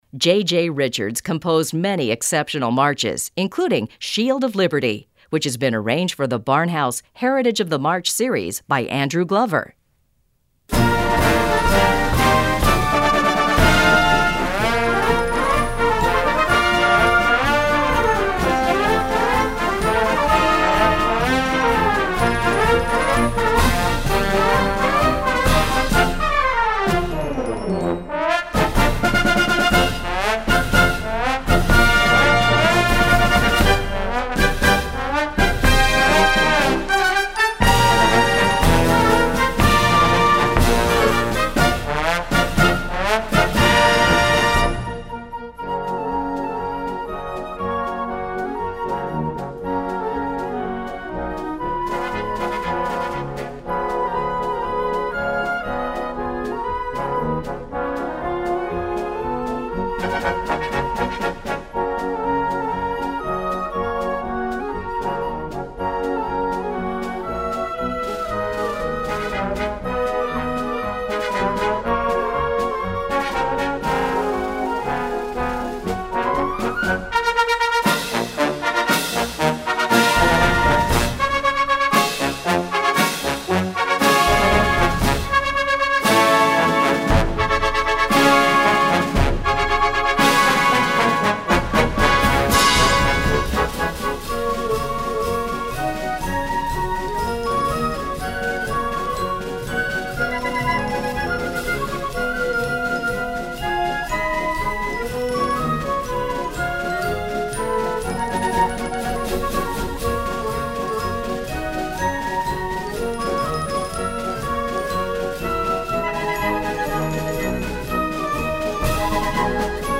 Voicing: Concert March